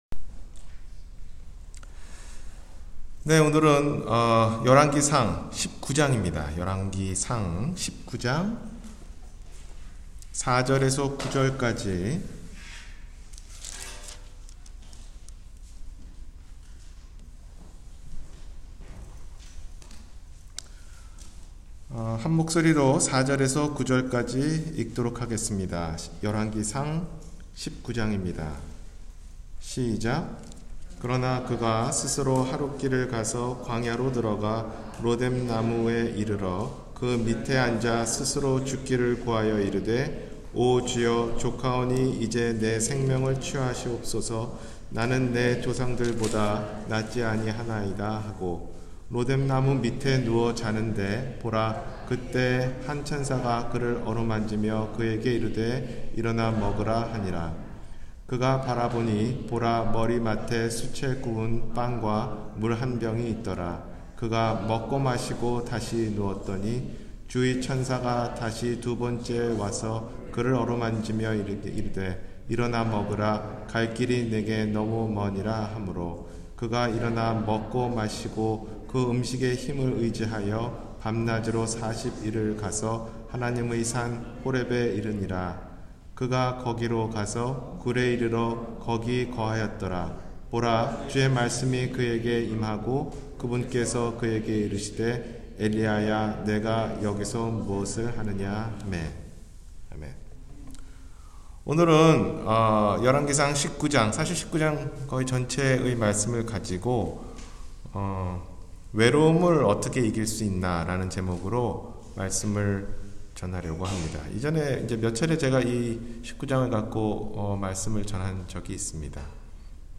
외로움을 어떻게 이길 수 있나 – 주일설교